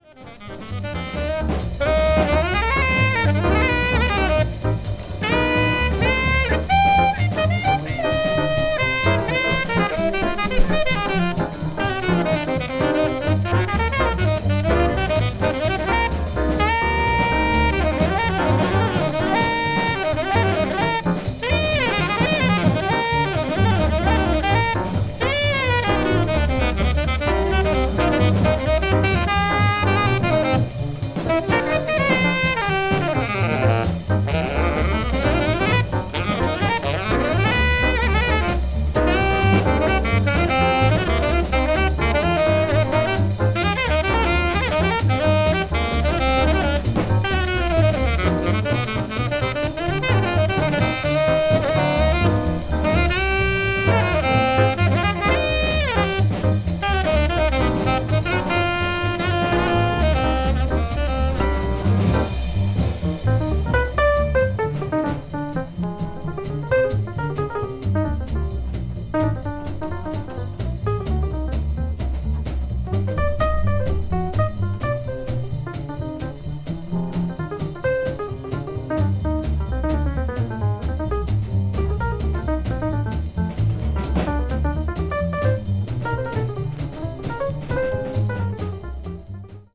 音質良し。